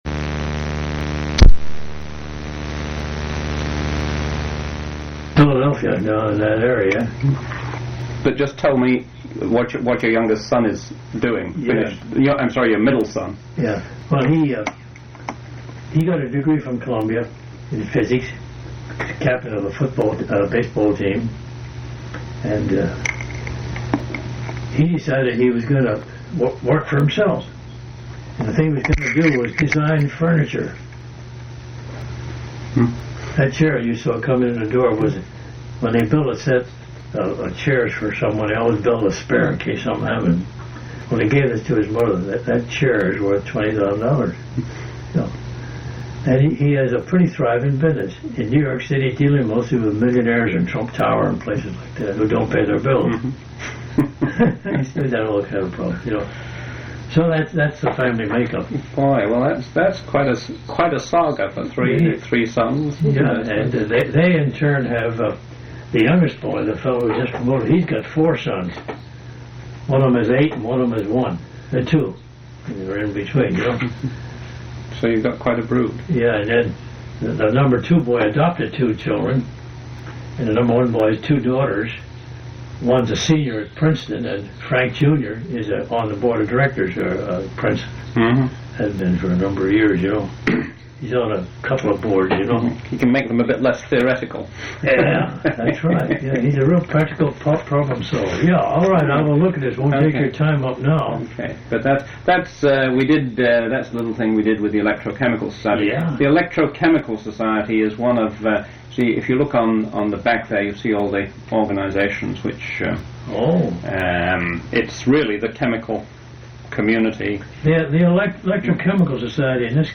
Oral history interview
Oral histories